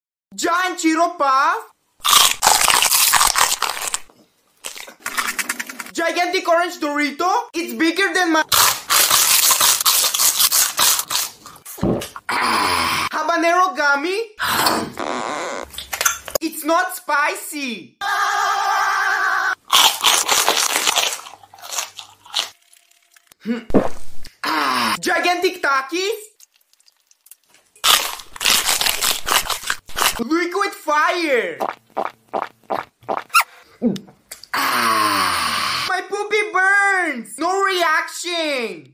Extreme Spicy Orange ASMR!_ 🥵 sound effects free download